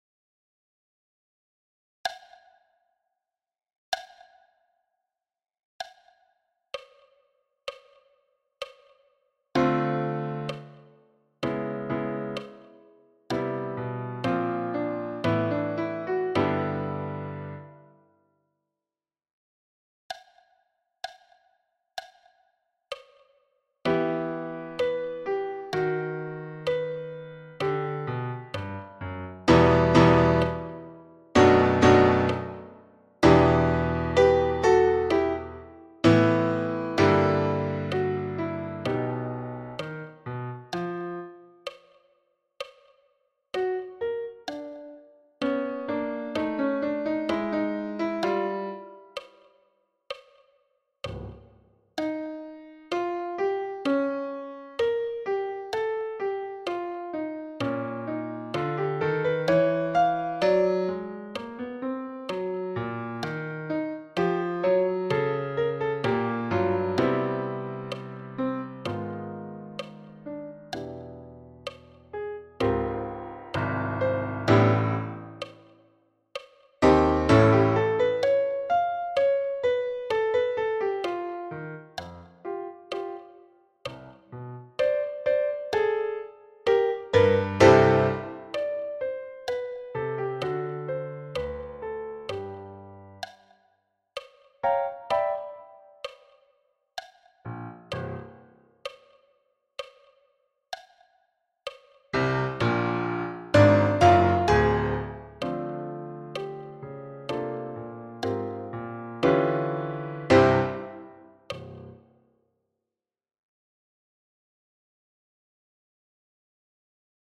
Caisse ballade à 64 bpm
Caisse-ballade-a-64-bpm.mp3